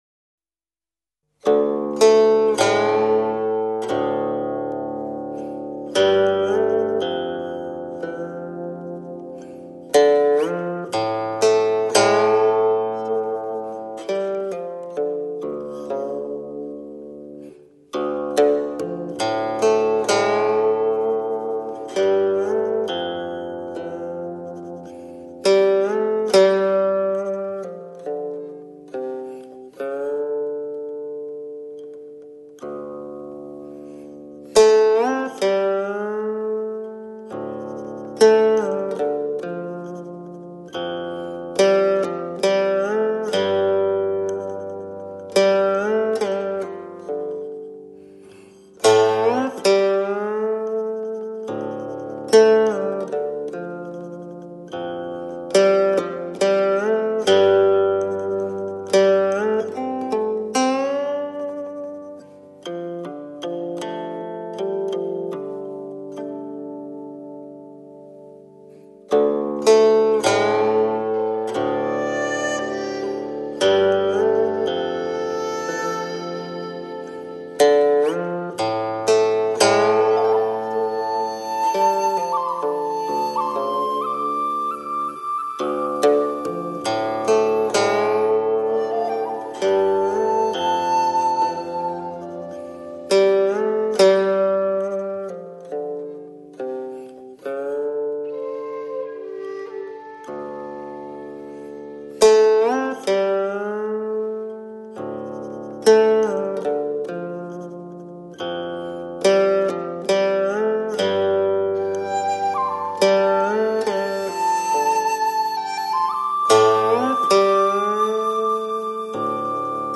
流派：古琴与萧